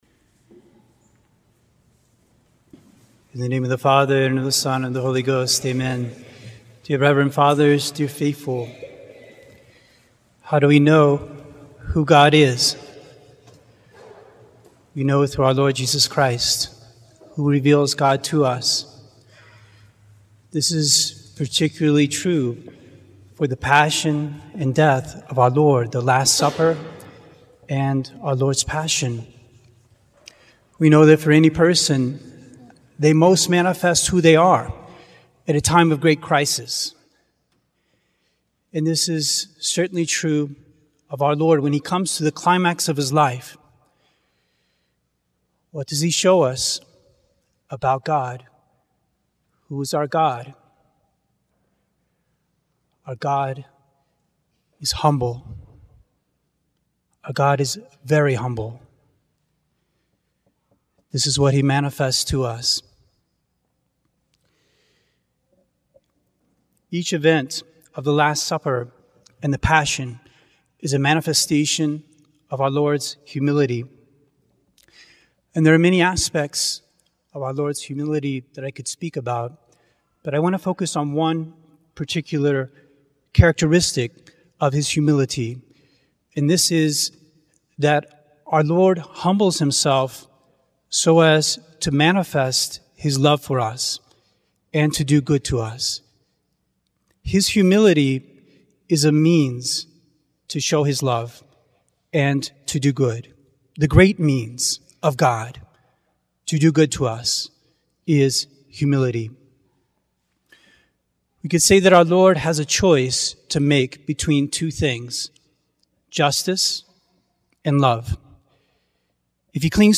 Life is a Contest of Love between God and Us, Sermon